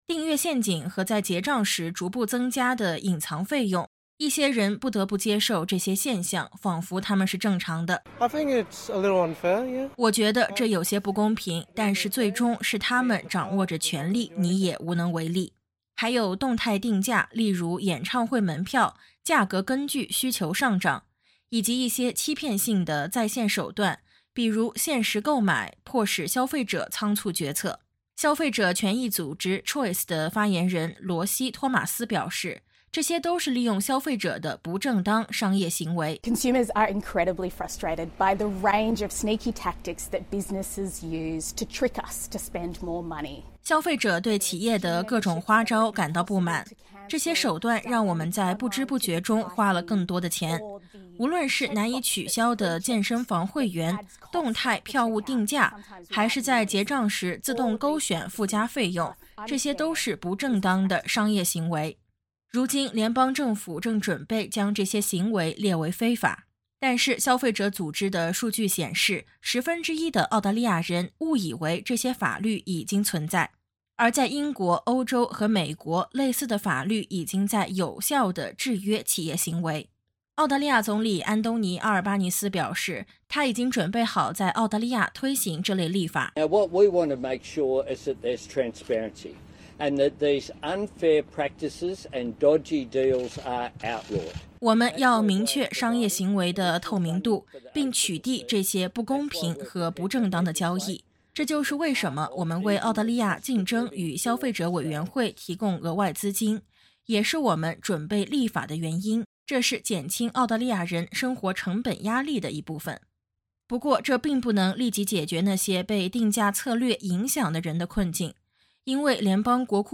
联邦政府正在采取行动，打击隐藏费用和难以取消的订阅等不正当的定价手段。健身房、航空公司、在线零售商和票务公司等企业成为重点整治对象，政府希望杜绝尚未被消费者法禁止的潜在有害行为。点击音频，收听综合报道。